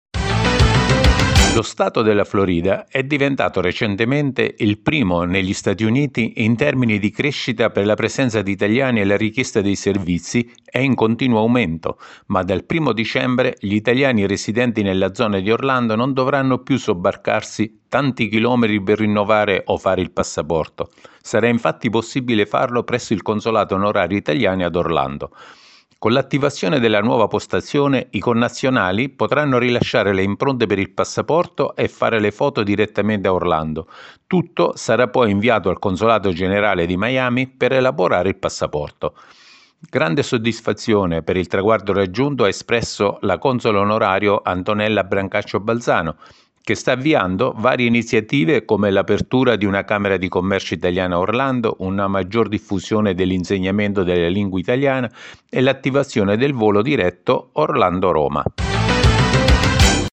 A Orlando nuova postazione per il rinnovo dei passaporti (audio notizia)